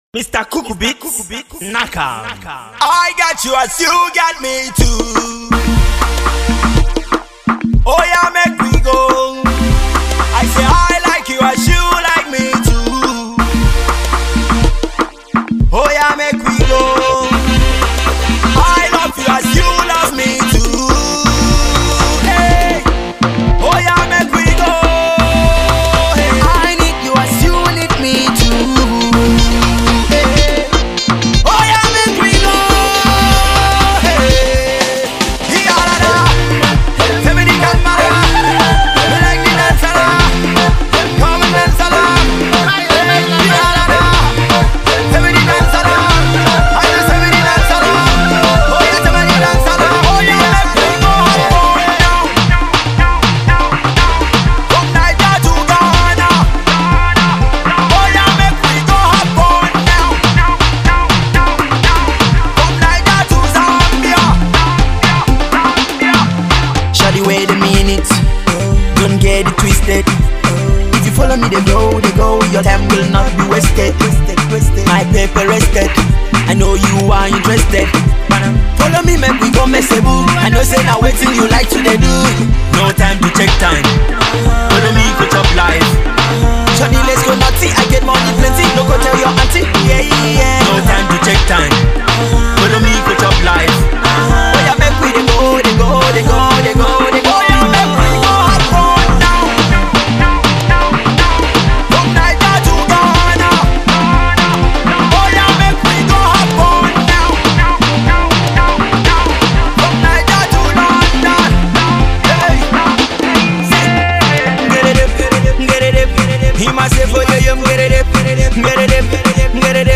Pop
Afro Pop